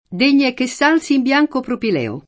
propilei [propil$i] s. m. pl. — raro il sing.: Degna è che s’alzi in bianco propileo [